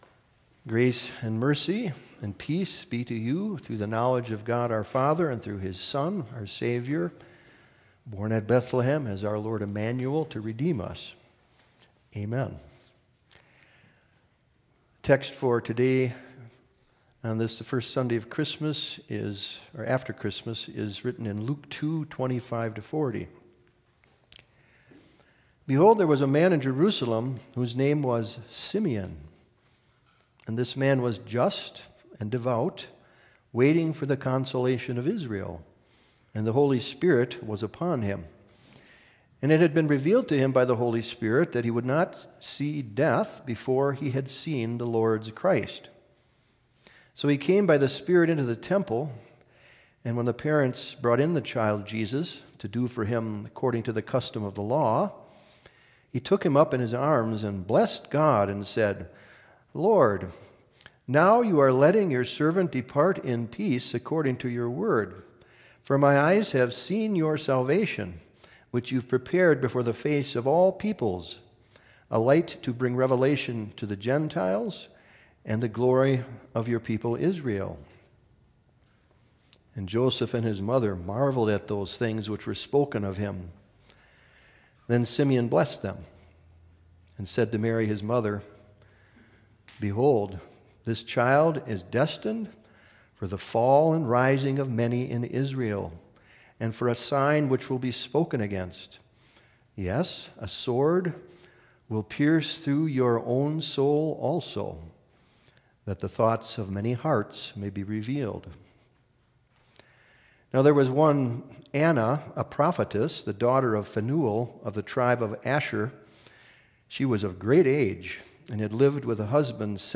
Dec28_25SermonOnly.mp3